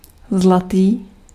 Ääntäminen
Synonyymit doré jaune blond sandre grande tortue doré bleu Ääntäminen France: IPA: [dɔ.ʁe] Haettu sana löytyi näillä lähdekielillä: ranska Käännös Ääninäyte Adjektiivit 1. zlatý {m} Suku: m .